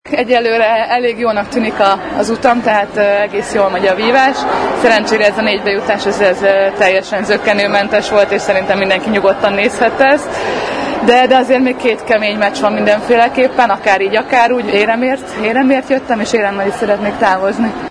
A négy közé jutás után a Marosvásárhelyi Rádiónak is nyilatkozott: